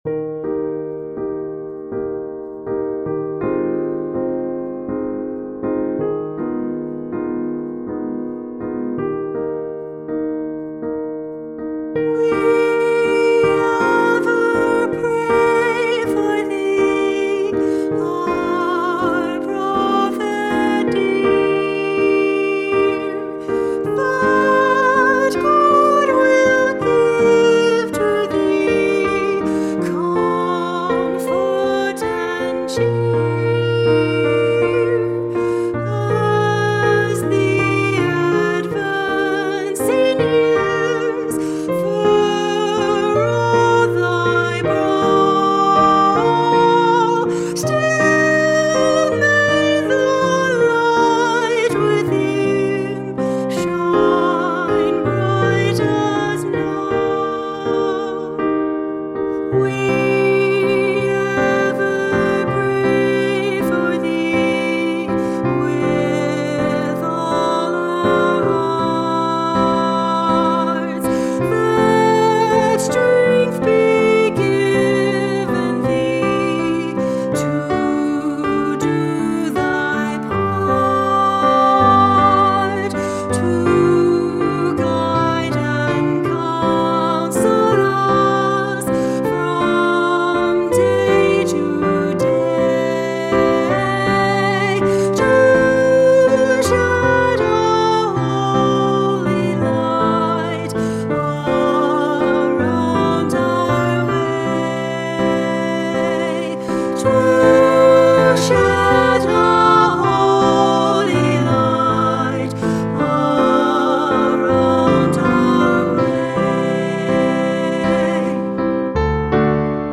Vocal Duet for Medium and Medium-high Voices with Piano